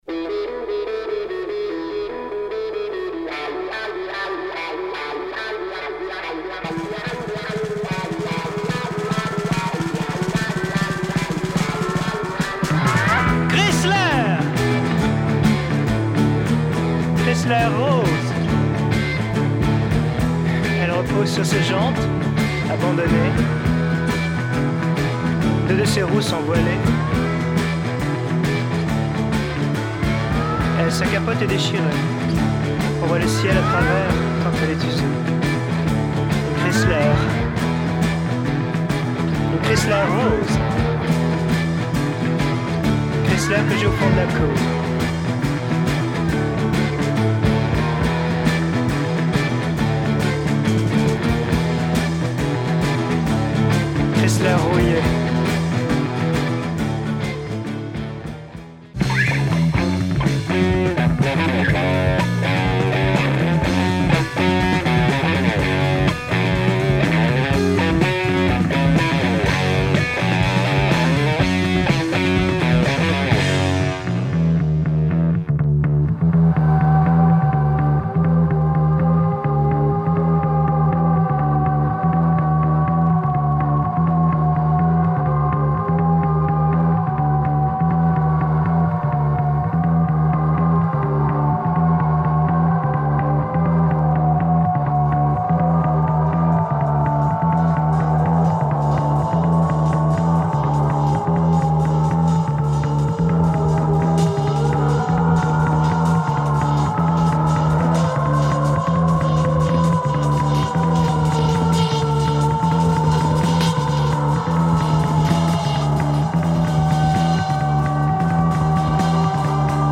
Crazy baroque psychedelic music